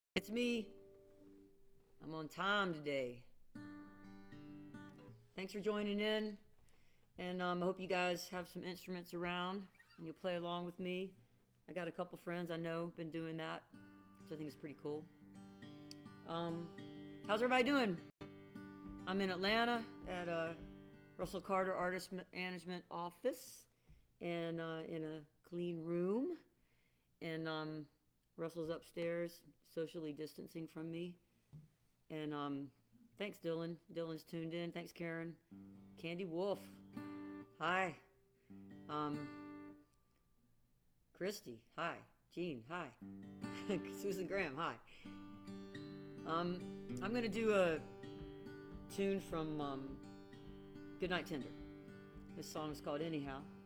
(captured from the facebook live video stream)
02. talking with the crowd (0:53)